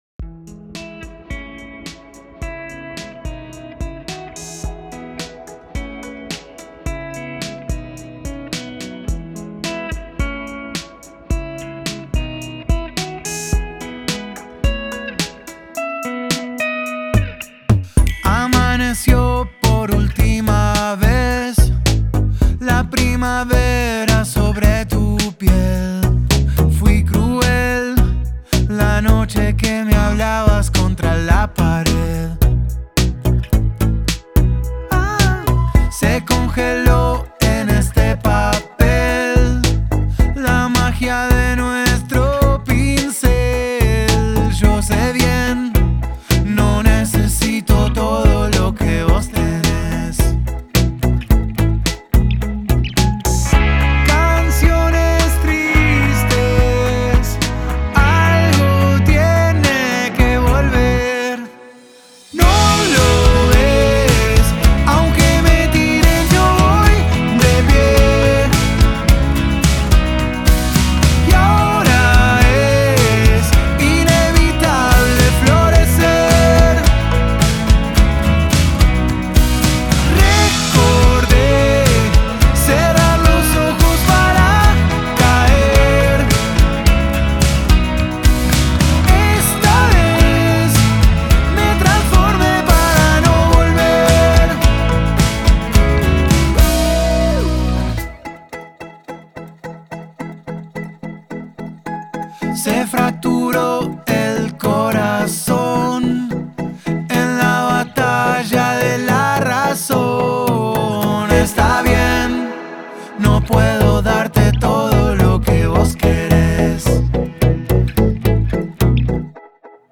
melodía pop
el cantante argentino